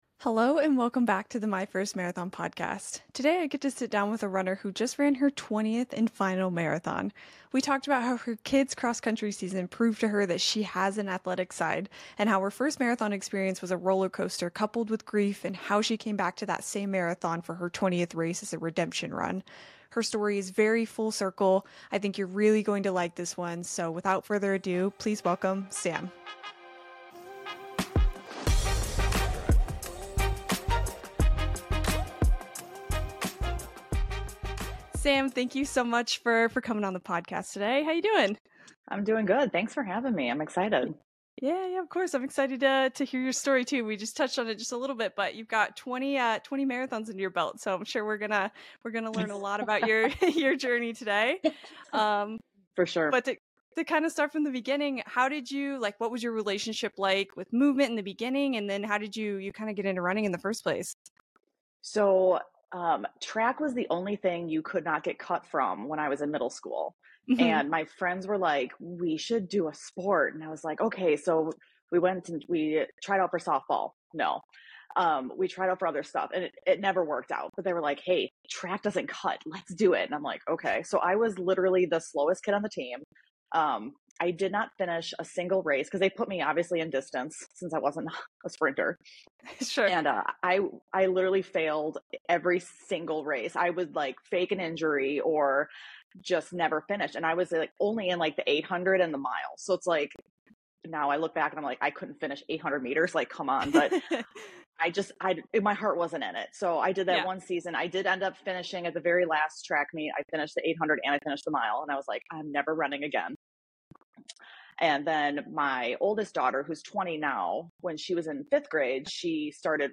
Join us for an inspiring interview about resilience, healing through running, and the power of a second chance.